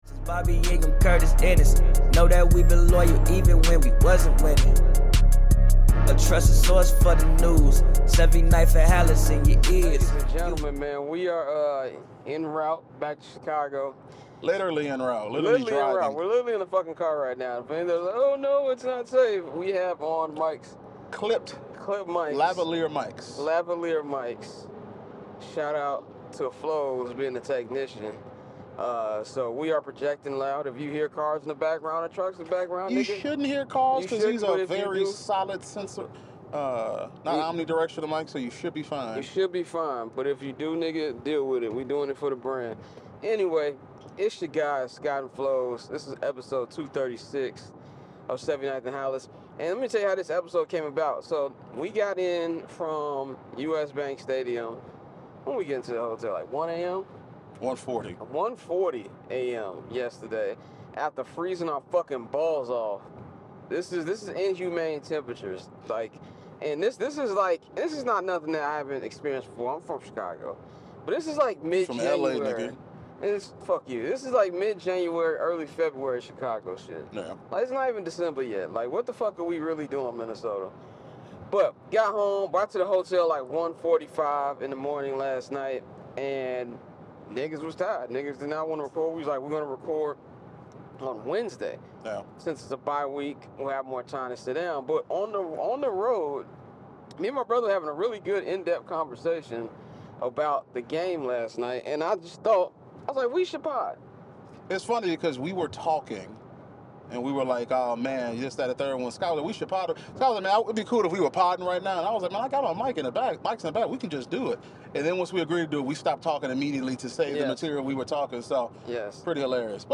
while literally on the road back from Minnesota